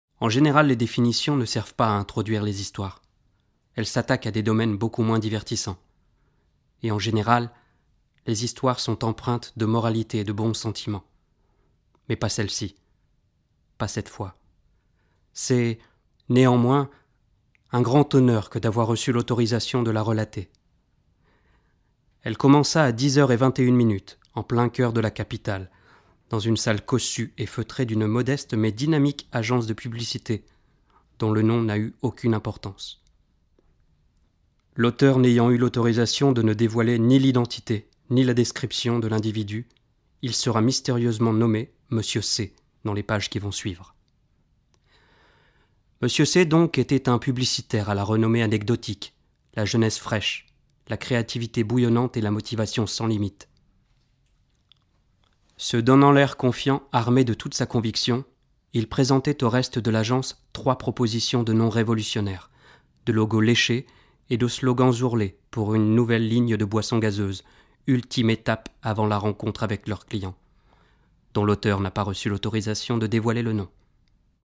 Acteur professionnel, voix-off, lecteur livres audio
Sprechprobe: Werbung (Muttersprache):